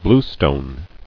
[blue·stone]